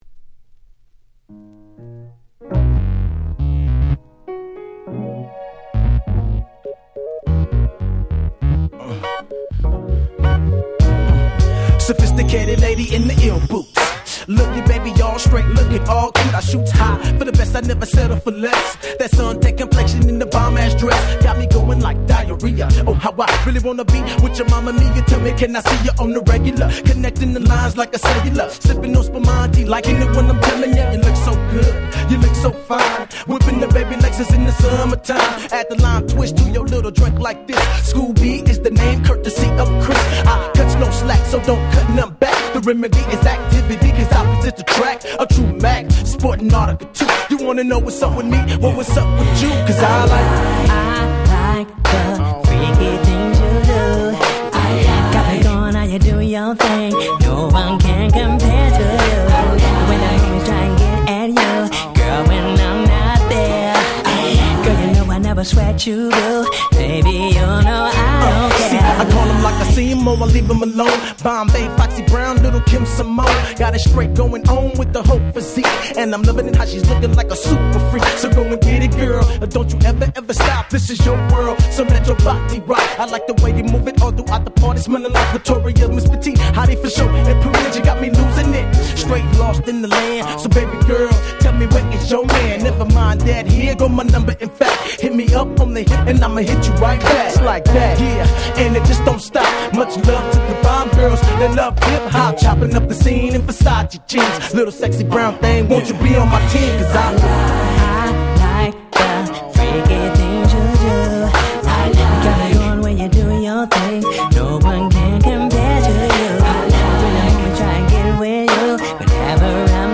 03' Very Nice West Coast Hip Hop !!
(Radio Edit)
クイック エルデバージ 00's ウエッサイ ウエストコースト G-Rap Gangsta Rap